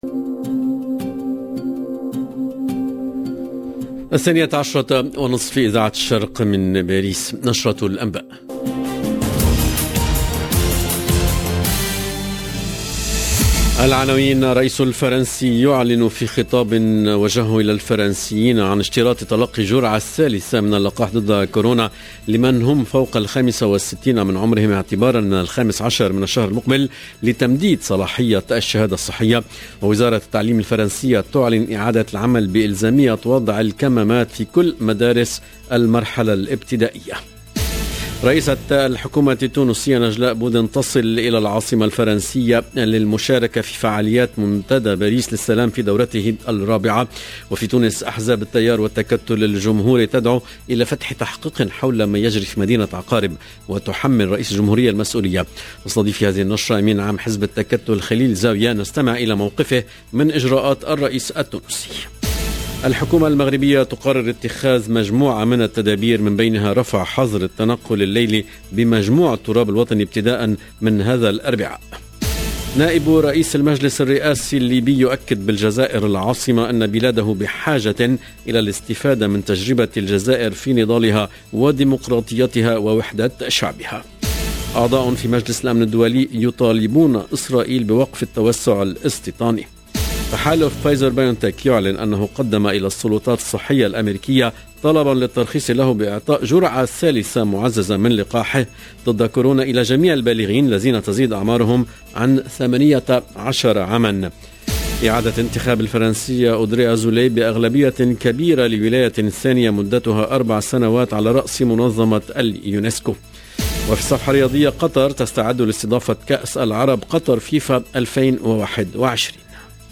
LE JOURNAL EN LANGUE ARABE DE MIDI 30 DU 10/11/21